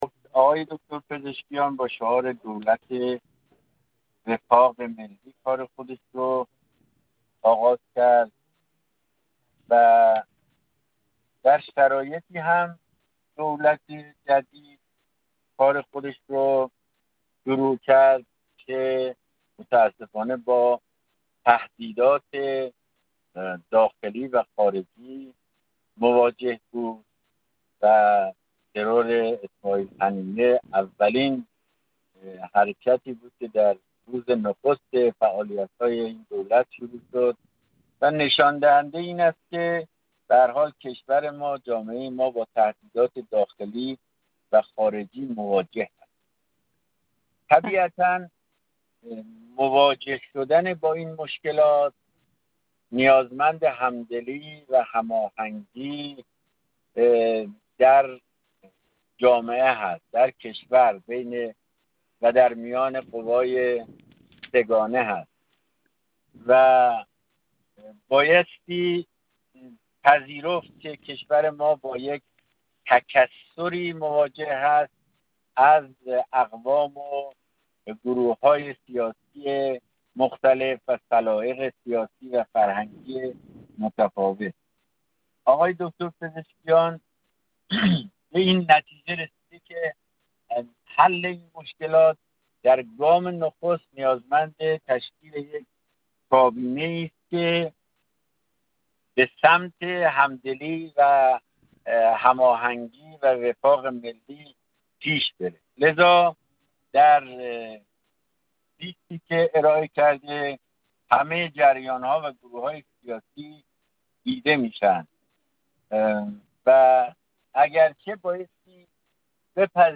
جواد آرین‌منش، جامعه‌شناس
در این راستا جواد آرین‌منش، جامعه‌شناس و عضو پیشین کمیسیون فرهنگی مجلس در گفت‌وگو با خبرنگار ایکنا با اشاره به ترکیب کابینه پیشنهادی گفت: رئیس‌جمهور با شعار دولت وفاق ملی کار خود را آغاز کرد.